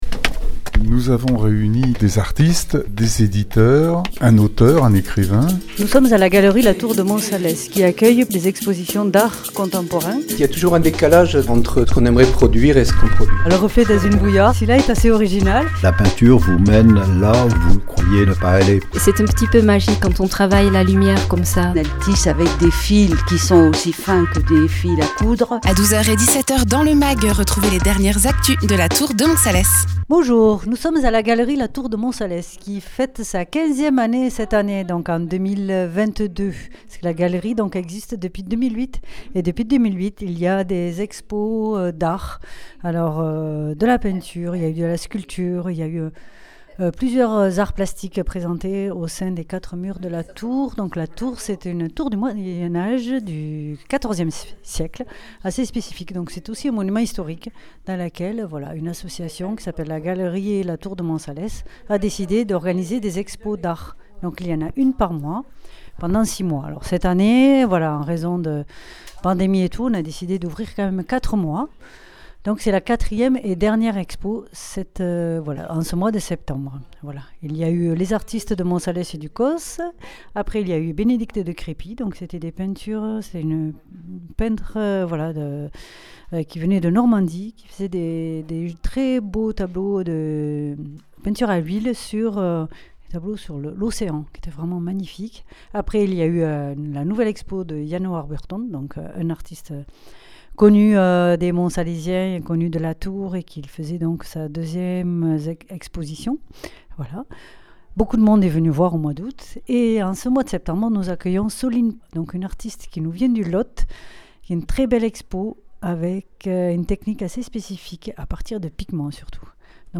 Reportage.
Interviews